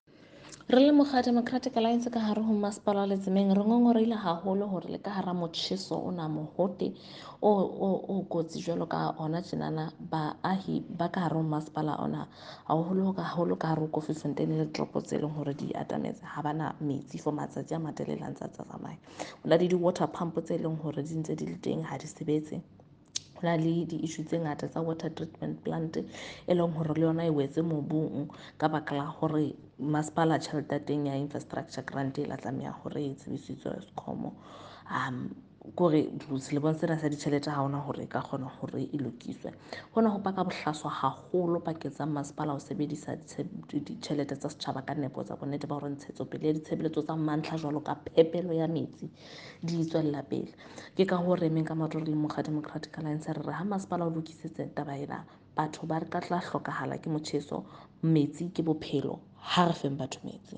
Sesotho by Karabo Khakhau MP.
Sotho-voice-Karabo-11.mp3